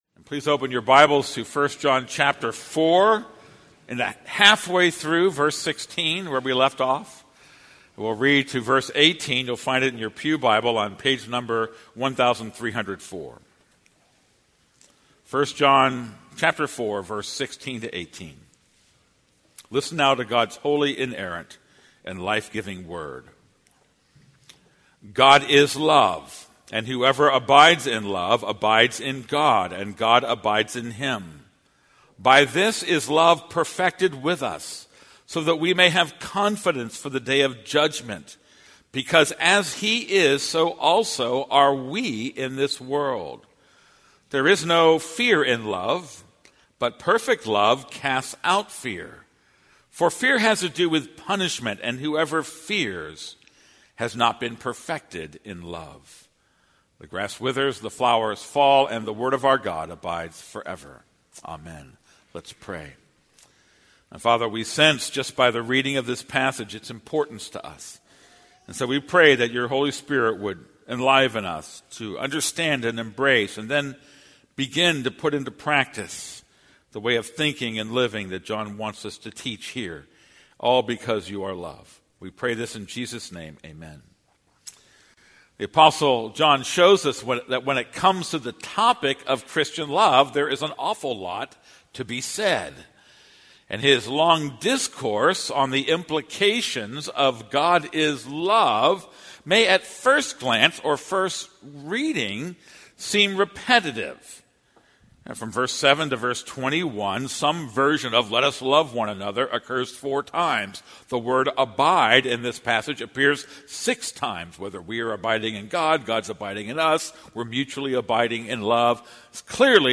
This is a sermon on 1 John 4:16-18.